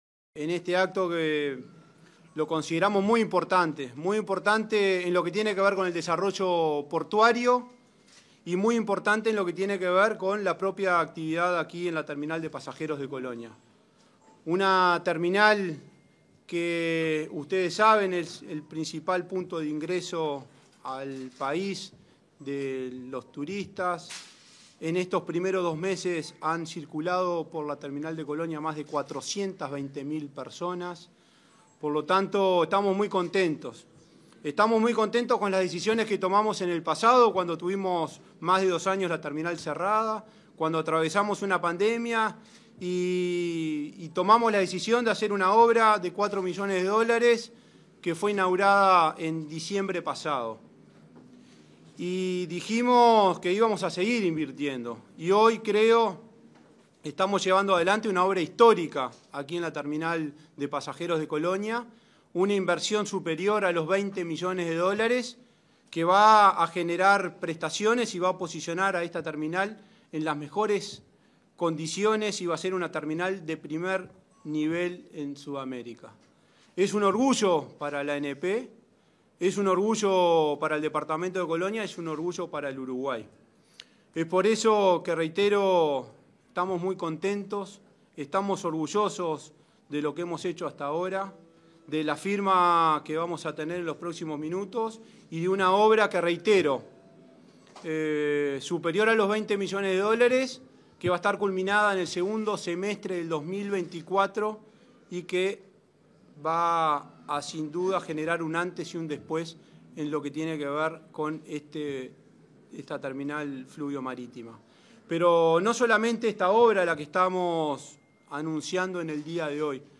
Palabras del presidente de la ANP, Juan Curbelo, y el intendente de Colonia, Carlos Moreira
Palabras del presidente de la ANP, Juan Curbelo, y el intendente de Colonia, Carlos Moreira 23/02/2023 Compartir Facebook X Copiar enlace WhatsApp LinkedIn Con motivo de la firma de un contrato de obras con empresas constructoras en el puerto de Colonia, este 23 de febrero, se expresaron el presidente de la Administración Nacional de Puertos (ANP), Juan Curbelo, y el intendente departamental, Carlos Moreira.